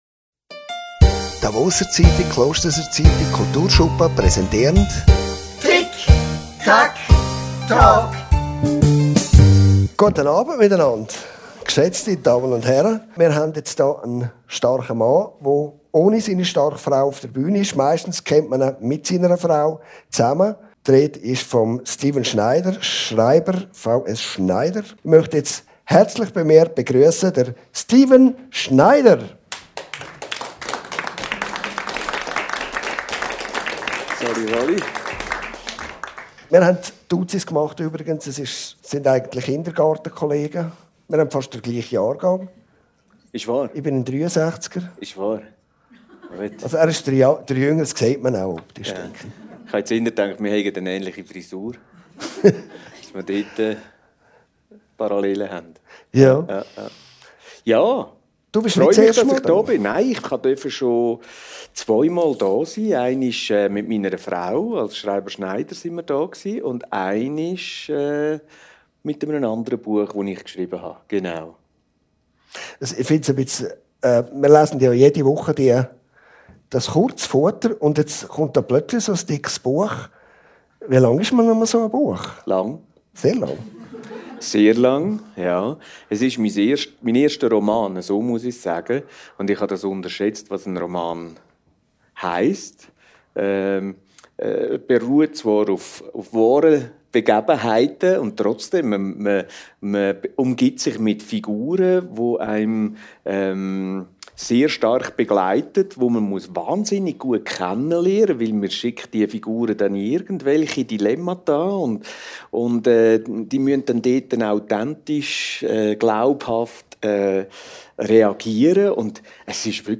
Talkshow und Podcast, präsentiert von der «Davoser Zeitung», «Klosterser Zeitung», Kulturschuppen Klosters und der Gemeindebibliothek Klosters.